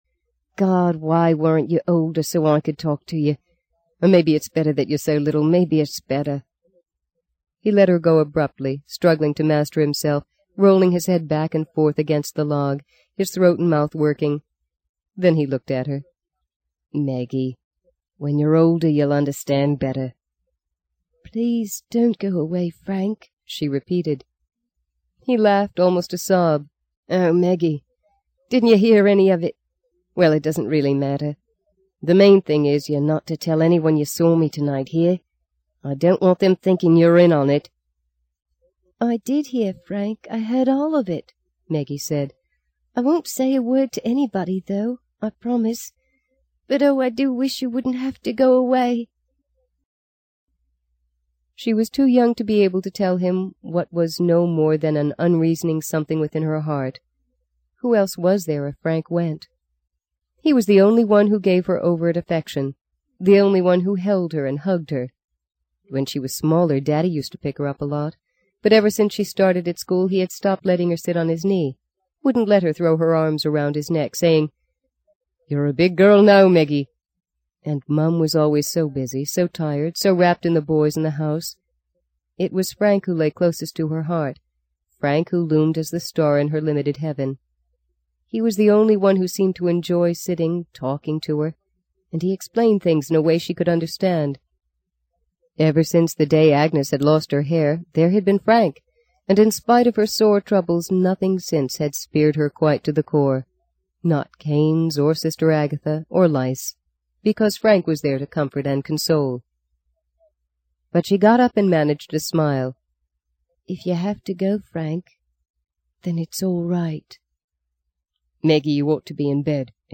在线英语听力室【荆棘鸟】第二章 26的听力文件下载,荆棘鸟—双语有声读物—听力教程—英语听力—在线英语听力室